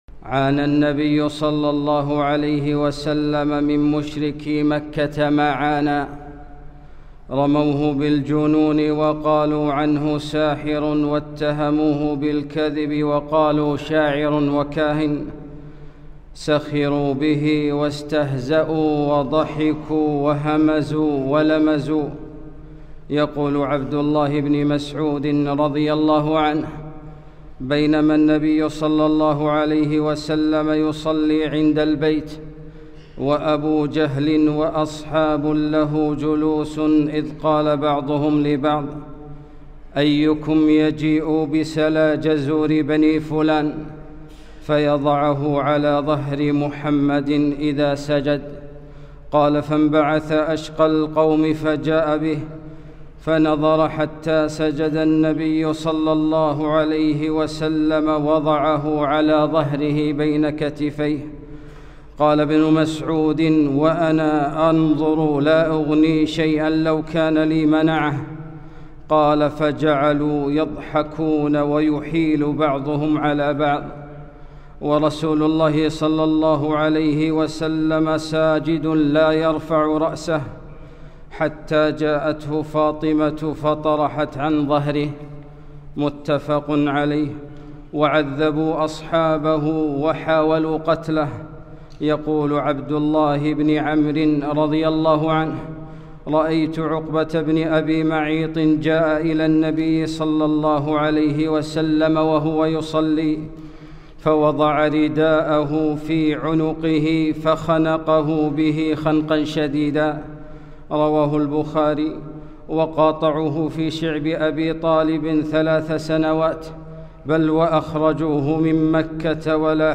خطبة - معاناة النبي صلى الله عليه وسلم